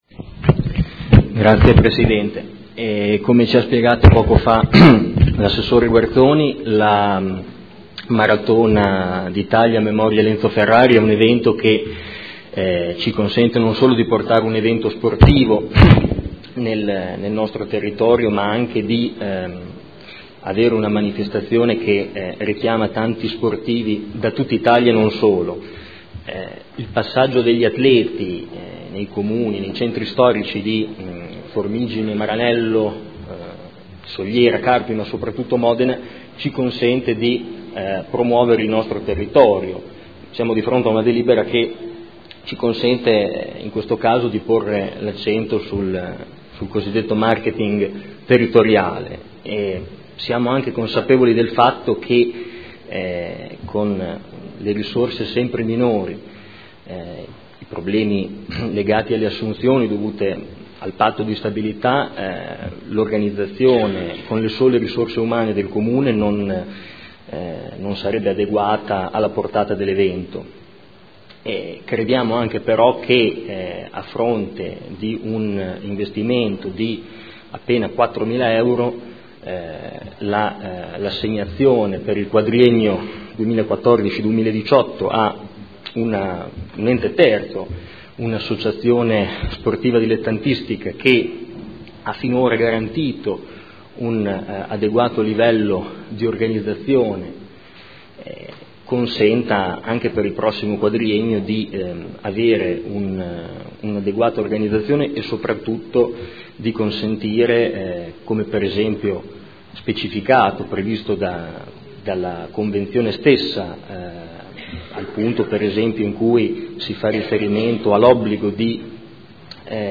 Seduta del 18 settembre.
Dichiarazioni di voto